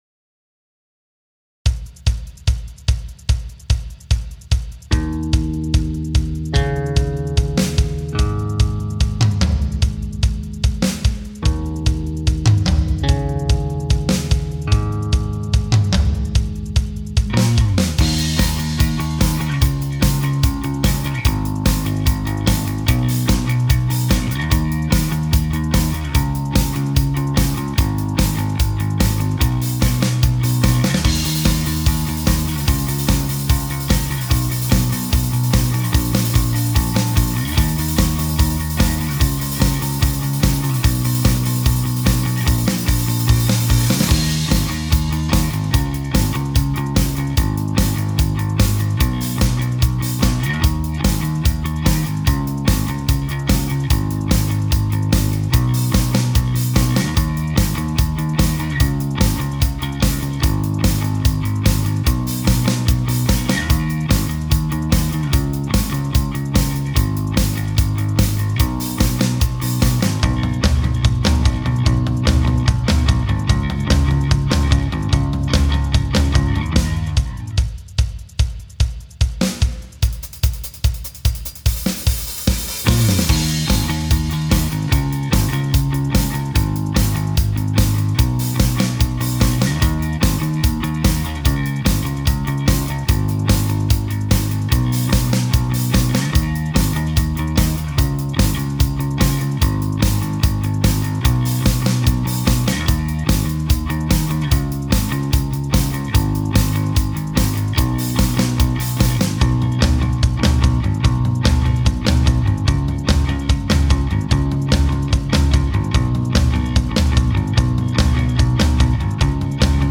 BPM : 147
Tuning : Eb
Without vocals
Based on the BBC live version